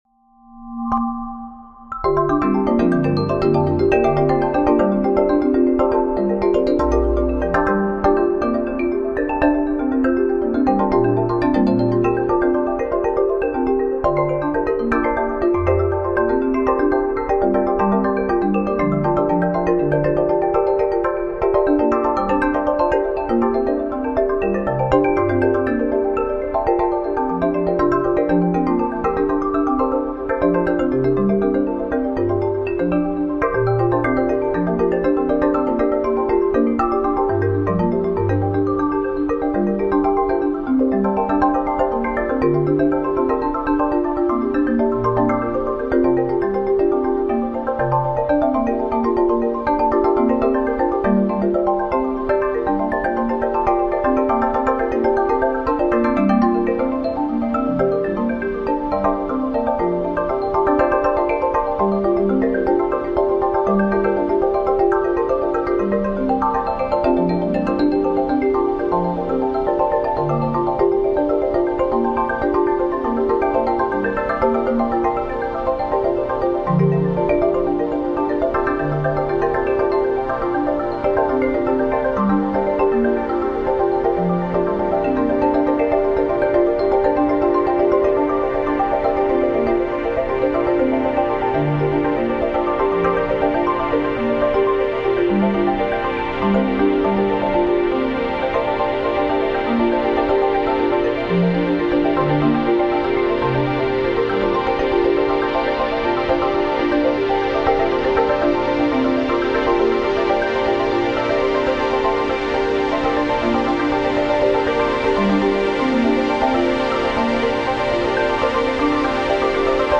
A unique representation of the vast scope of the Chanda Source Catalog is found in this sonification, the translation of astronomical data into sound. This sonification encompasses a new map that includes 22 years of Chandra observations across the sky. Because many X-ray sources have been observed multiple times over the life of the Chandra mission, this sonification represents those repeated X-ray sightings over time through different notes.